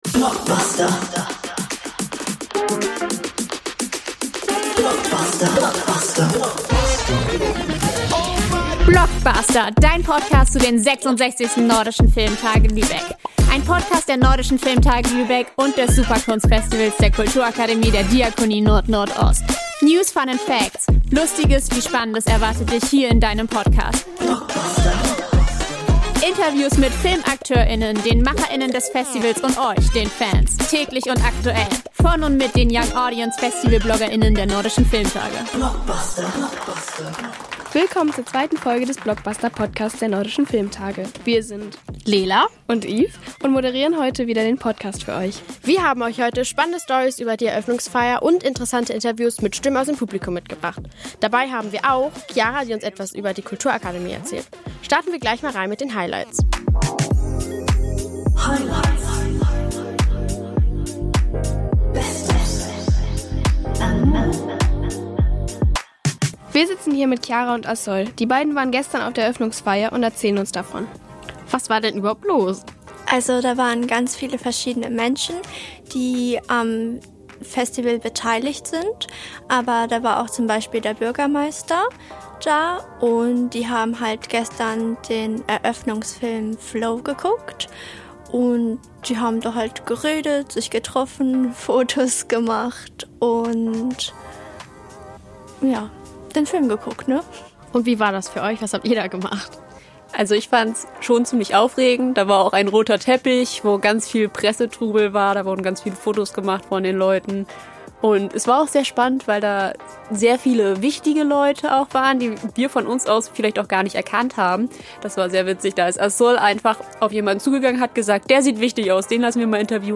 Wir erzählen euch von der Eröffnungsparty, haben einige Stimmen aus dem Publikum im Gepäck, eine spannende Kritik zu Lars ist LOL dabei und ihr erfahrt, was uns gestern peinliches passiert ist.